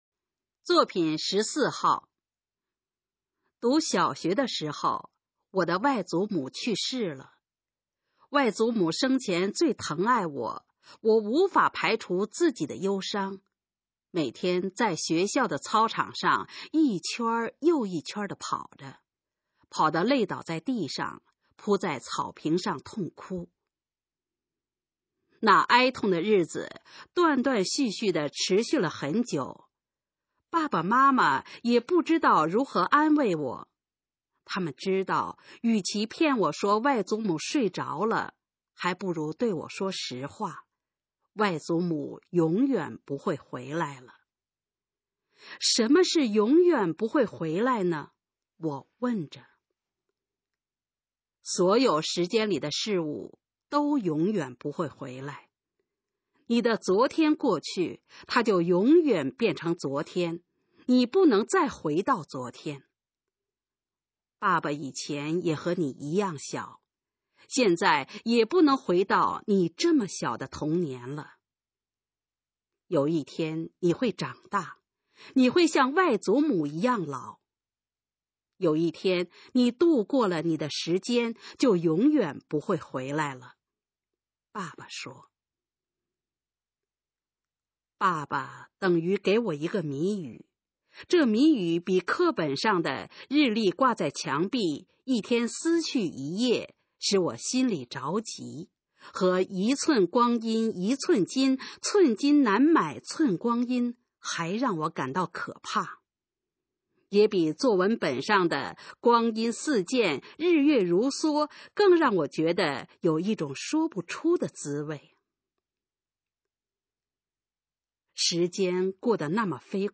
《和时间赛跑》示范朗读_水平测试（等级考试）用60篇朗读作品范读